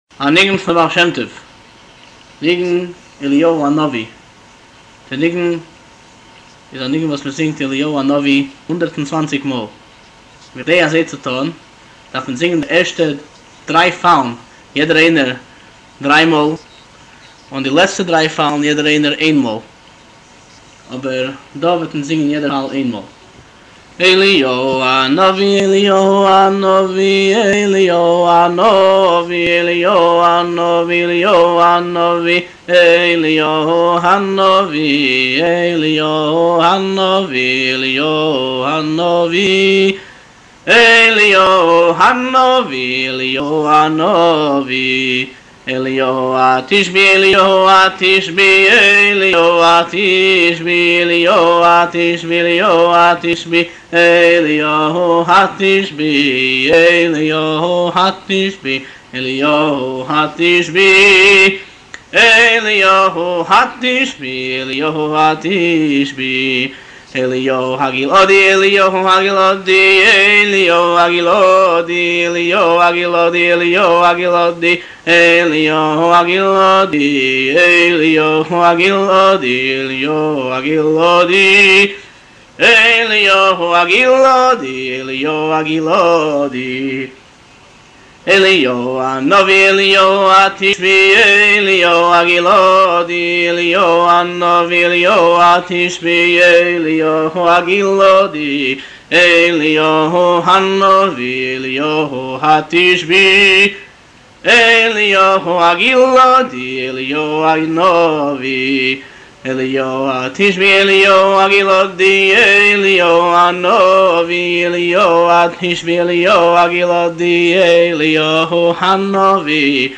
הניגון מורכב מ-4 חלקים, כאשר בחלק הראשון חוזרים שלוש פעמים על המקטע הכולל חזרה של עשר פעמים על הכינוי 'אליהו הנביא'. בחלק השני חוזרים שלוש פעמים על המקטע הכולל חזרה של עשר פעמים על הכינוי 'אליהו התשבי'. בחלק השלישי חוזרים שלוש פעמים על המקטע הכולל חזרה של עשר פעמים על הכינוי 'אליהו הגלעדי'.